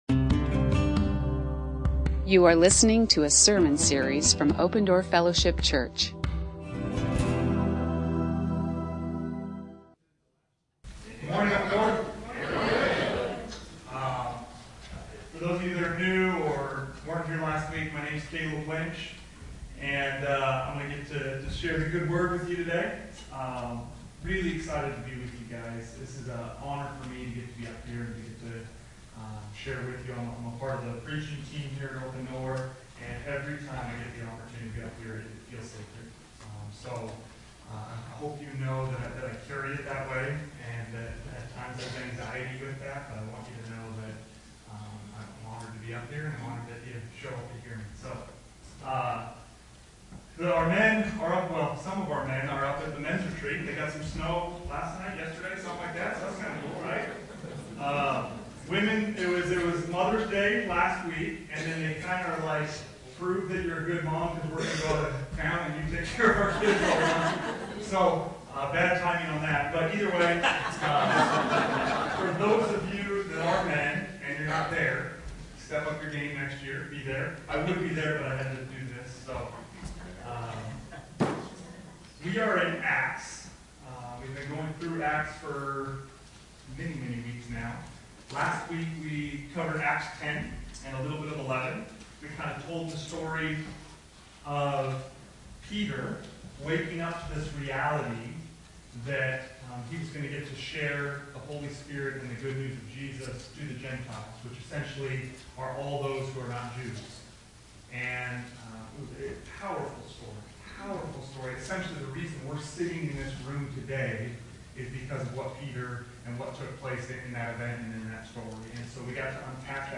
You are listening to an audio recording of Open Door Fellowship Church in Phoenix, Arizona.